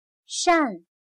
扇\shàn\ventilar; abanico; ventilador